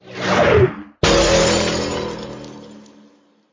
Angry Birds Space Planet Explosion Sound